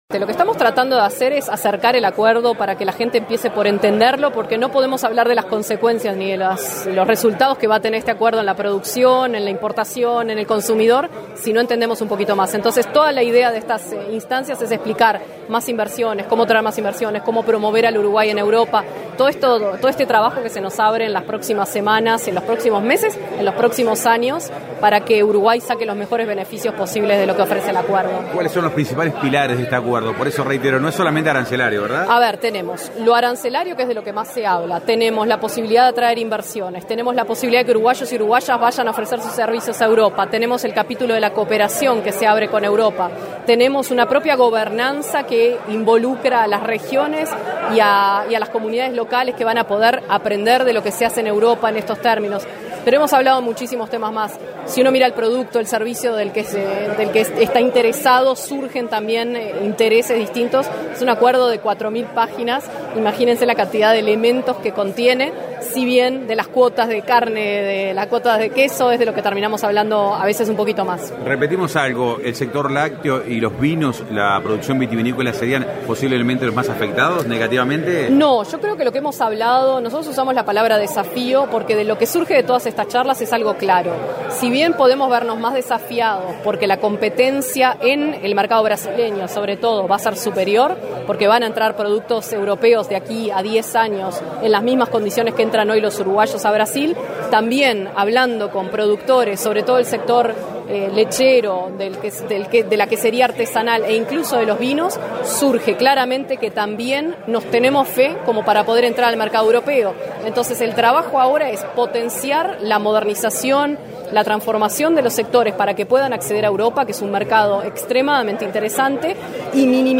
No podemos hablar de las consecuencias o resultados que tendrá el acuerdo sin antes entenderlo”, señaló la vice canciller Valeria Csukasi en la charla realizada en Nueva Helvecia.
El encuentro se desarrolló en el Hotel Nirvana titulada: “Acuerdo Mercosur – Unión Europea: Desafíos y oportunidades para el sector agropecuario”.
VALERIA-CSUKAZI.-Vice-Canciller-Tratado-Mercosur-UE-ok.mp3